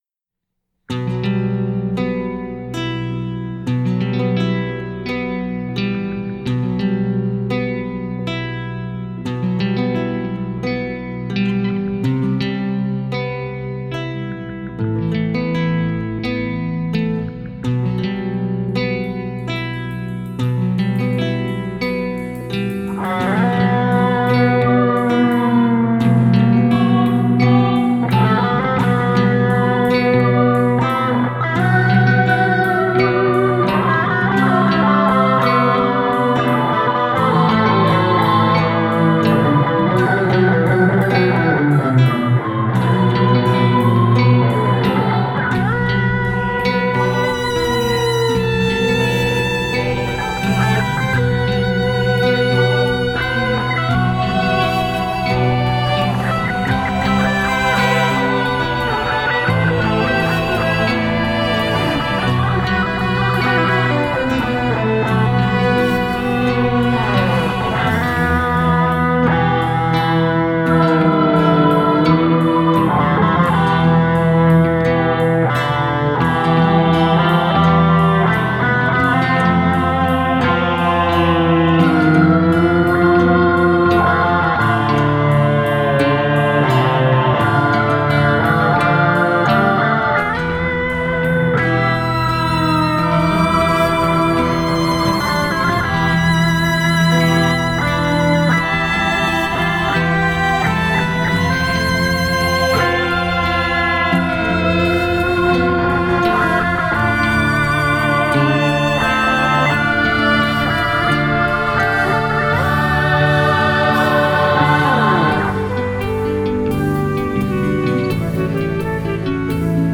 Рок Инструментальная музыка